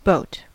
Ääntäminen
US : IPA : [boʊt]